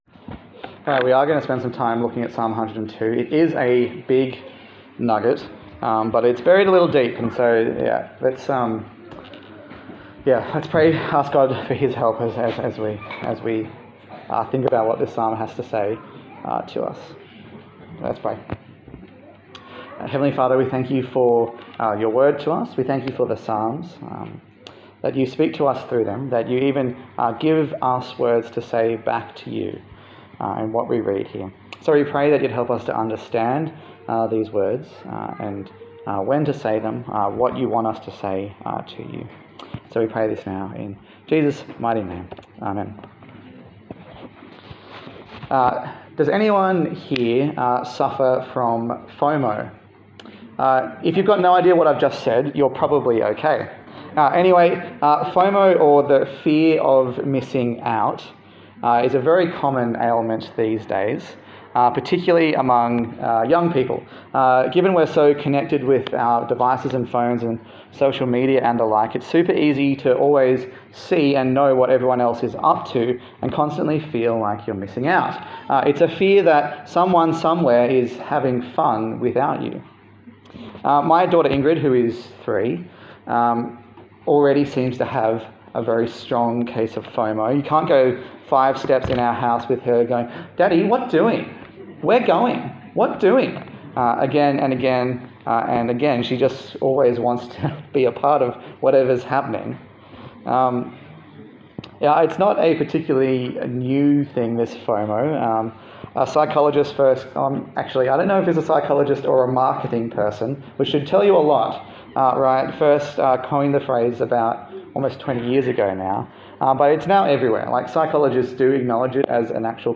Psalm 102 Service Type: Sunday Service A sermon in the series 'Songs for Summer' featuring the book of Psalms.